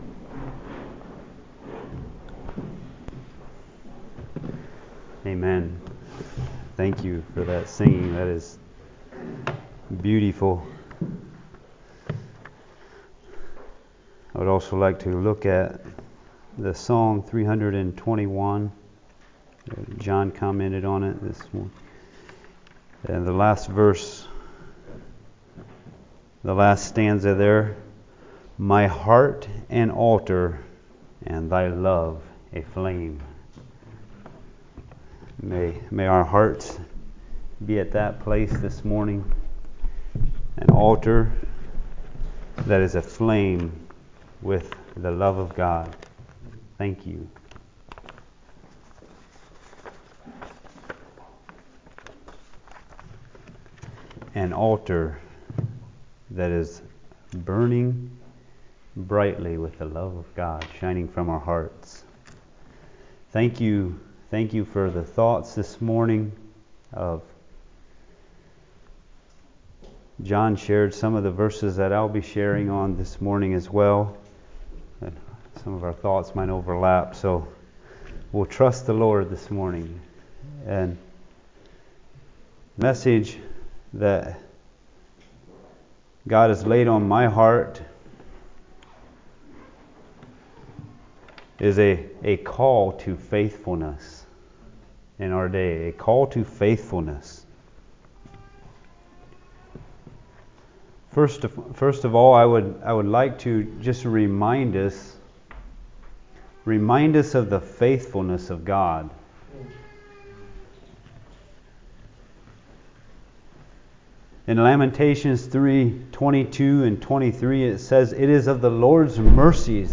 Message
A message from the series "2025 Messages."